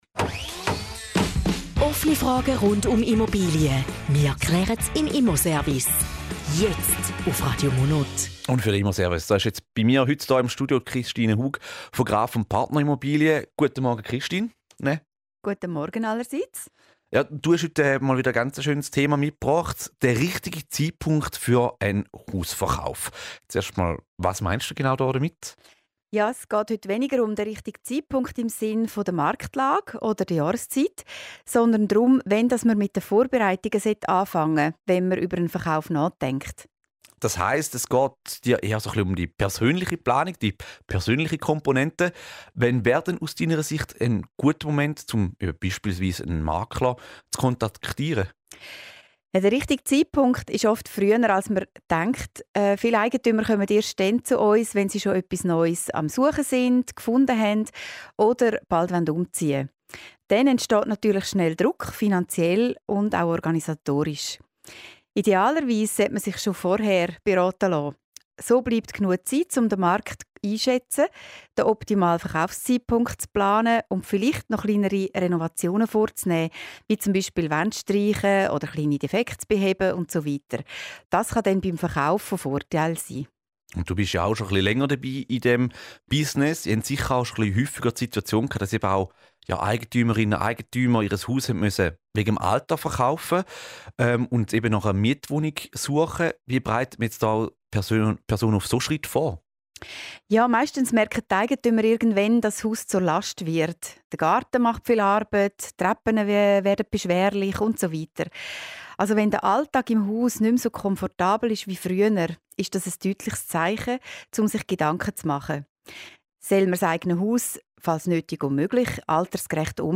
Zusammenfassung des Interviews zum Thema "Hausverkauf - wann ist der richtige Zeitpunkt":